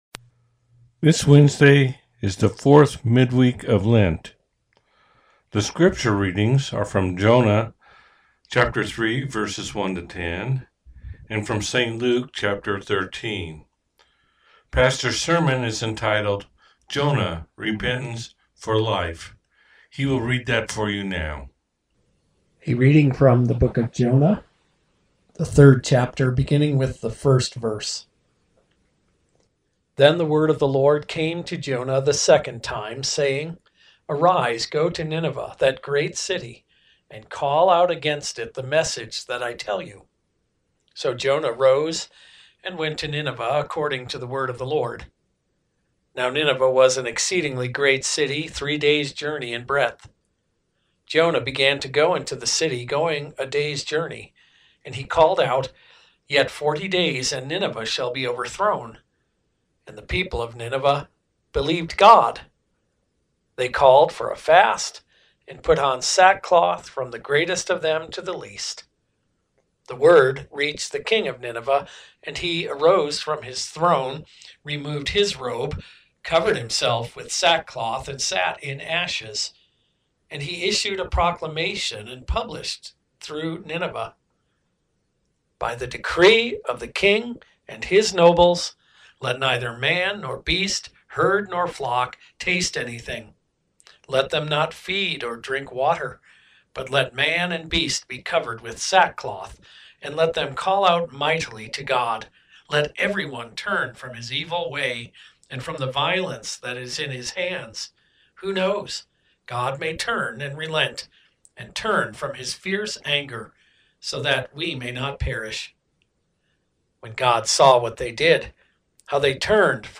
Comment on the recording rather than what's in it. Midweek Lent sermon from Peace With Christ Lutheran Church in Fort Collins.